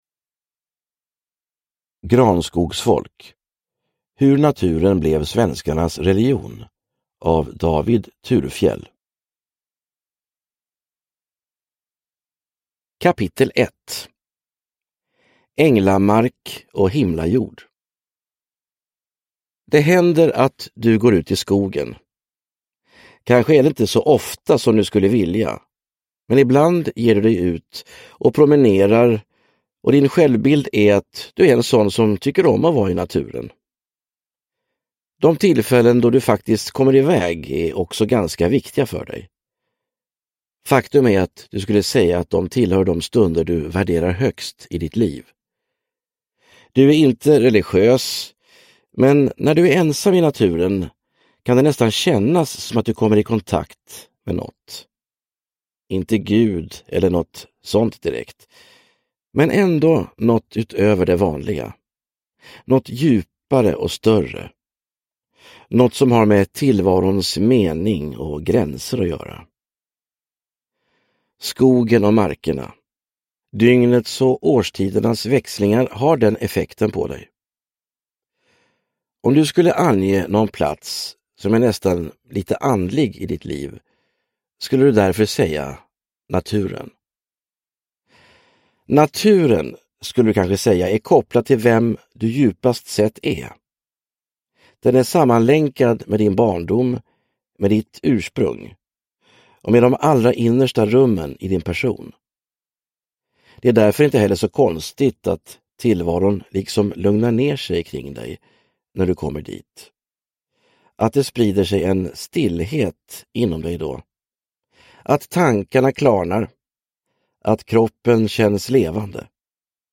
Granskogsfolk : hur naturen blev svenskarnas religion – Ljudbok – Laddas ner